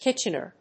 /ˈkɪtʃʌnɝ(米国英語), ˈkɪtʃʌnɜ:(英国英語)/